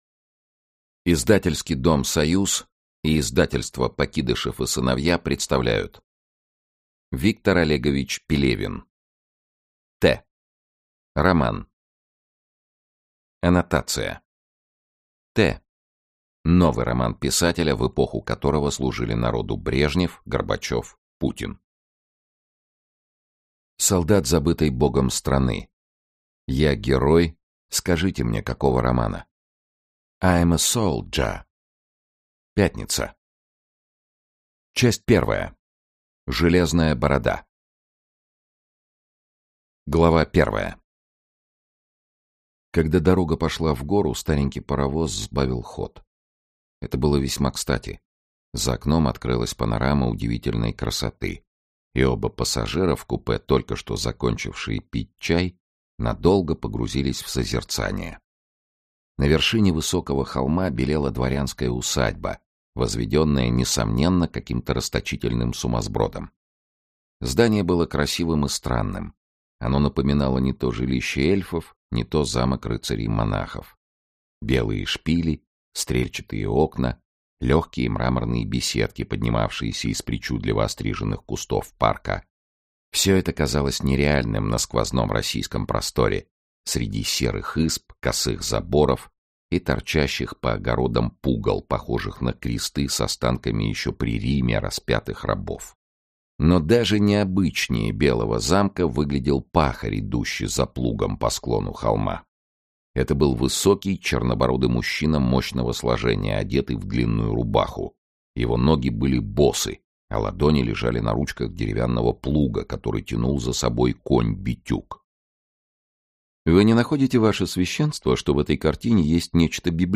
Аудиокнига t | Библиотека аудиокниг
Aудиокнига t Автор Виктор Пелевин Читает аудиокнигу Сергей Чонишвили.